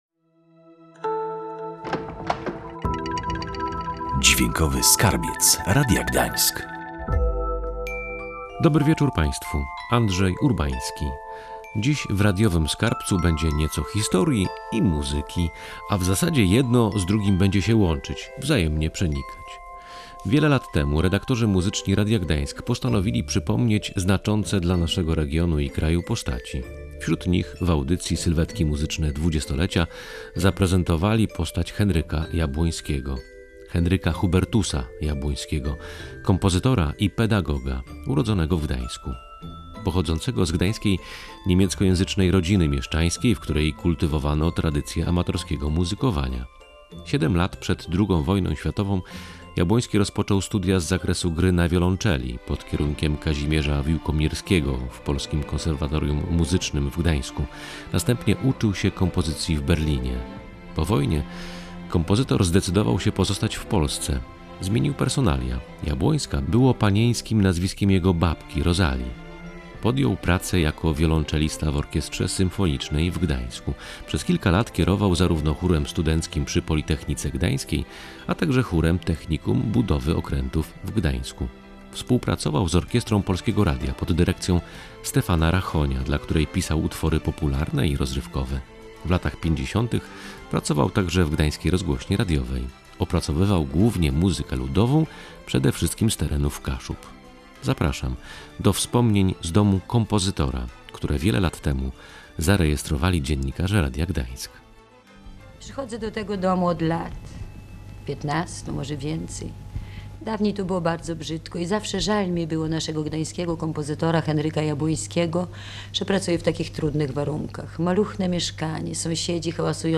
Zapraszamy do wspomnień z domu kompozytora, które wiele lat temu zarejestrowali dziennikarze Radia Gdańsk.